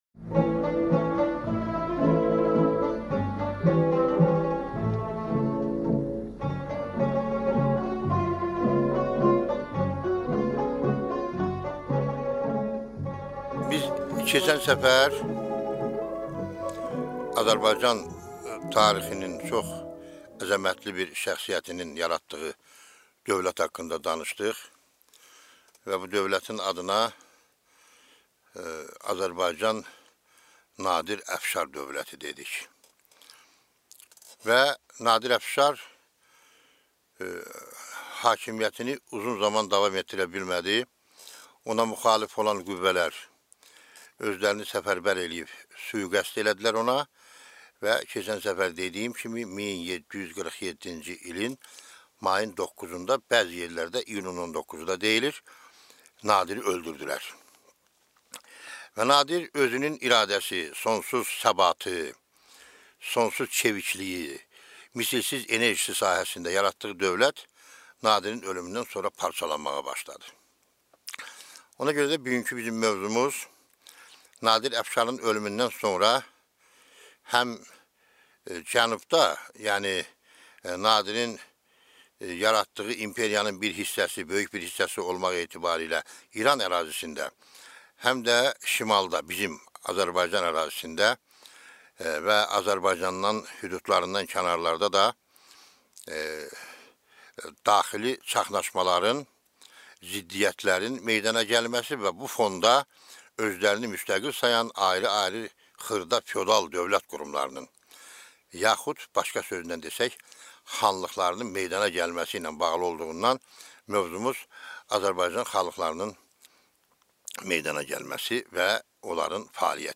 Аудиокнига Azərbaycan xanlıqlarının meydana gəlməsi və onların fəaliyyəti | Библиотека аудиокниг